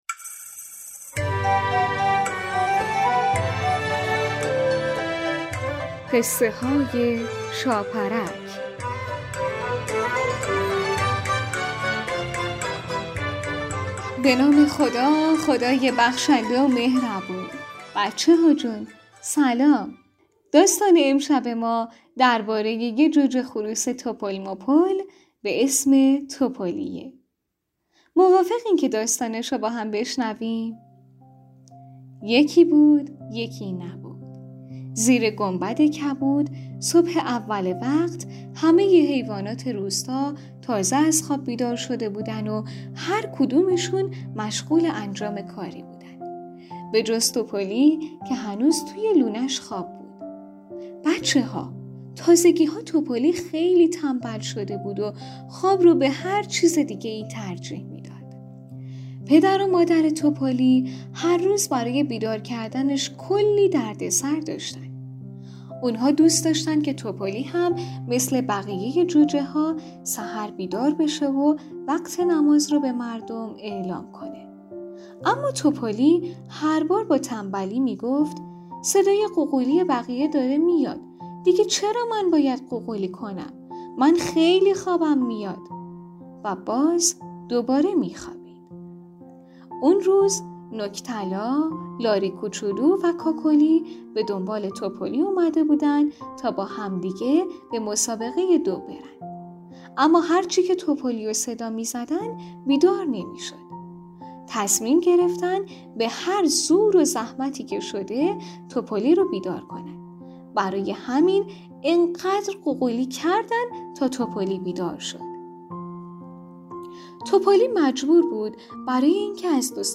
قسمت سی و هشتم برنامه رادیویی قصه های شاپرک ، داستان نمازی کودکانه مربوط به مربوط به جوجه خروسی به اسم تپلی است